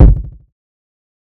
TC3Kick16.wav